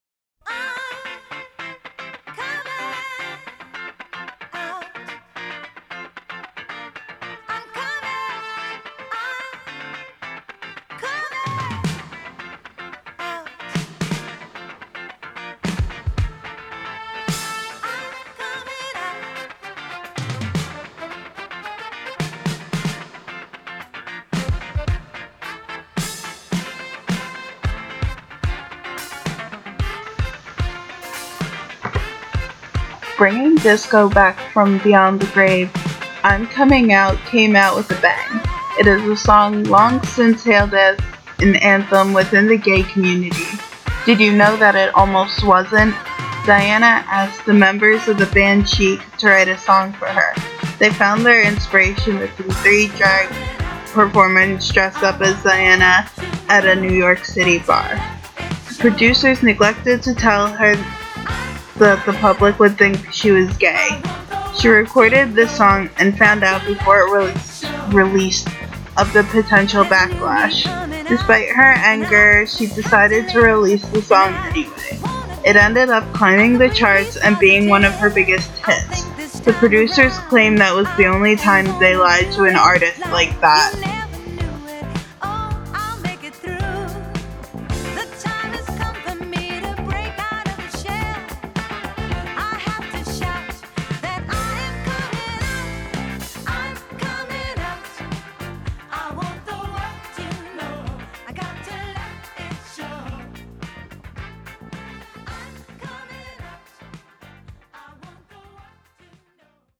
Bringing Disco back from beyond the grave